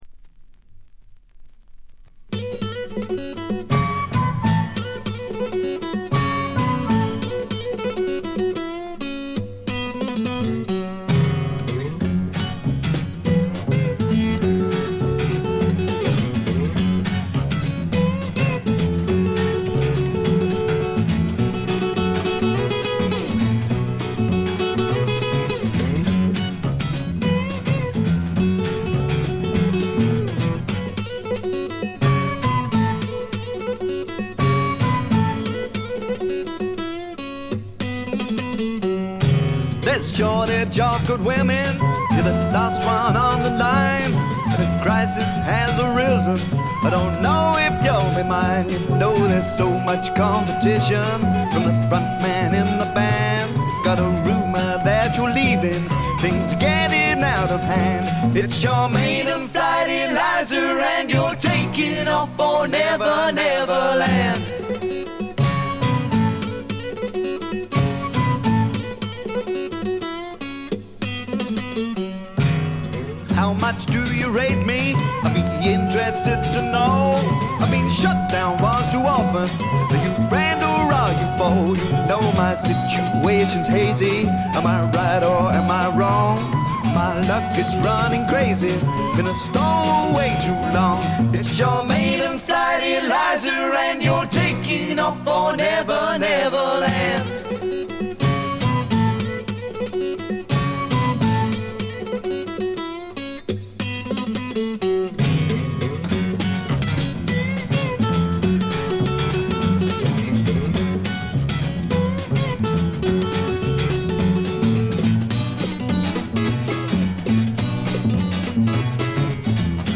軽微なチリプチ程度で全編良好に鑑賞できると思います。
試聴曲は現品からの取り込み音源です。